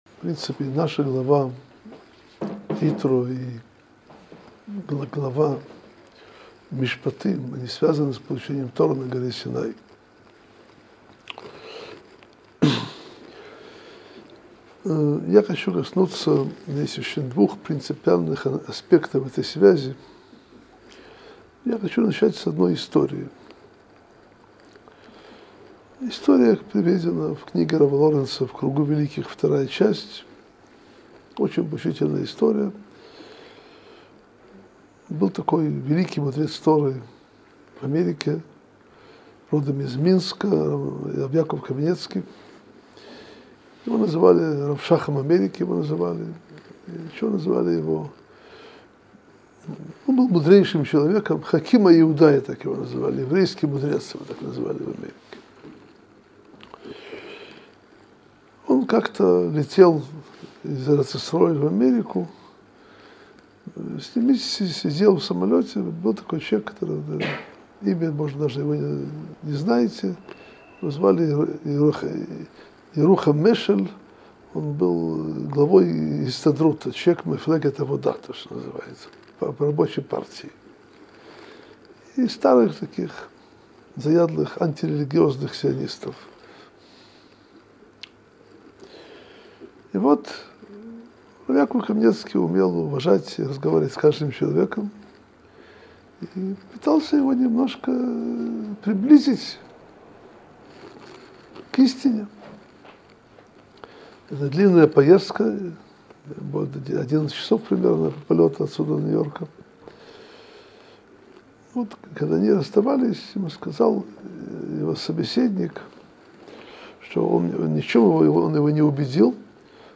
Содержание урока: Кого называли в Америке еврейским мудрецом?